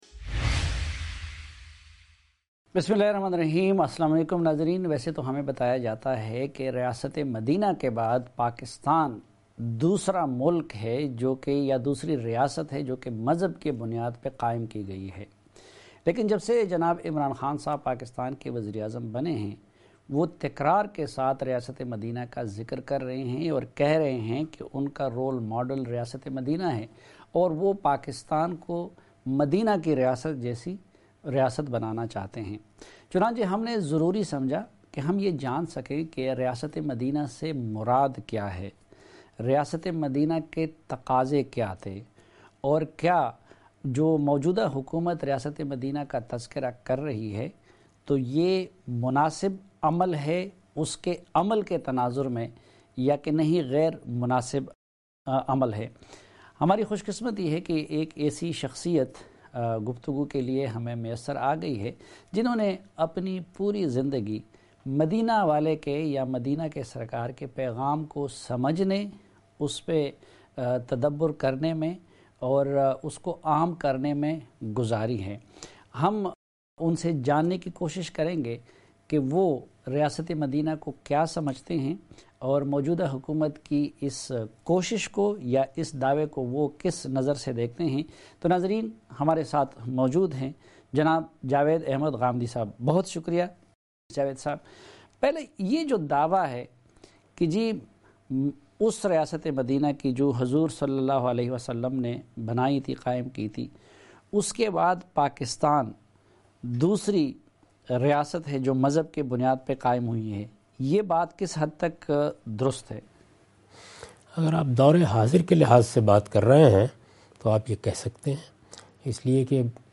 Javed Ahmad Ghamidi's Comments on important issue related to politics, economy, sociology, religion and international relations. It is a journey to achieve prosperous future by providing access to bitter truth through serious and thought provoking dialogue In Geo Tv's program Jirga with Saleem Safi.